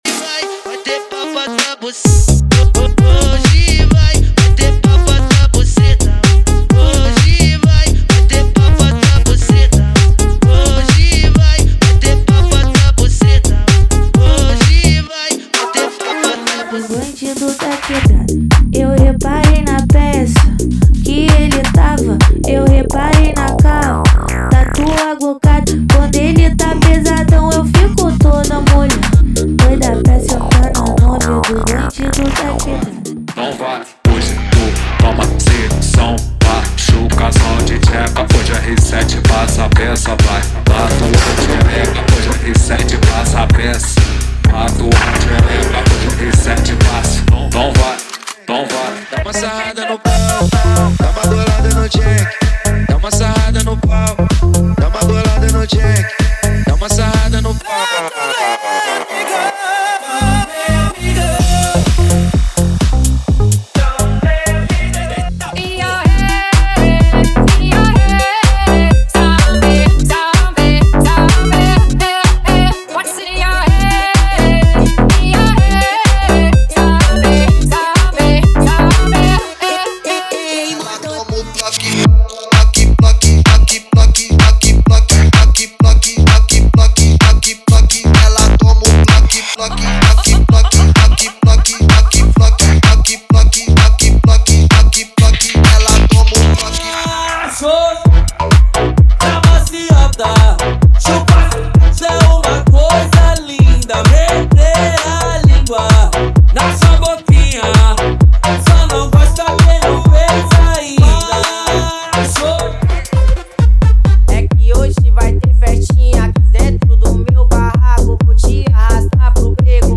MEGA MINIMAL
✔ Músicas sem vinhetas